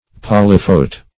Search Result for " polyphote" : The Collaborative International Dictionary of English v.0.48: Polyphotal \Pol`y*pho"tal\, Polyphote \Pol"y*phote\, a. [Poly- + Gr.